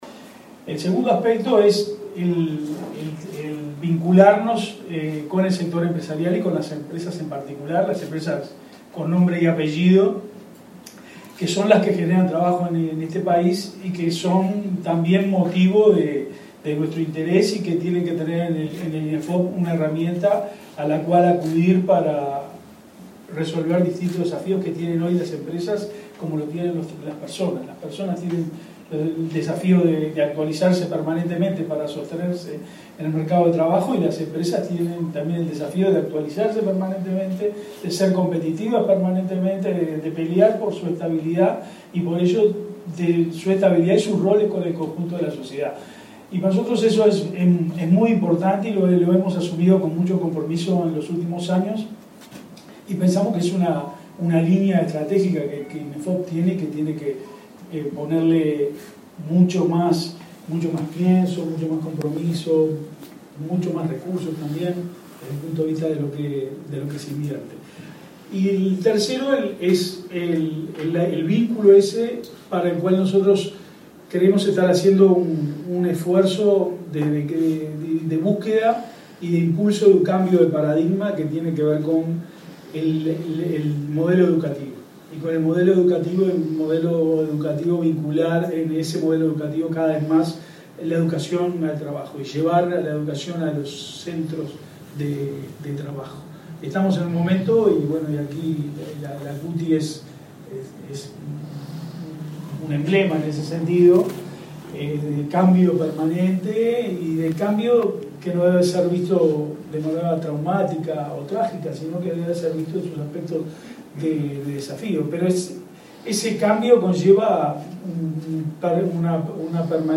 “Apostamos a la integración de diferentes colectivos que históricamente han tenido dificultades de acceso a oportunidades laborales”, enfatizó el director del Inefop, Eduardo Pereyra, este lunes 8 , durante la presentación en el LATU de un curso de Tester de Software para personas con discapacidad que incluye prácticas formativas en cinco empresas de ese parque tecnológico.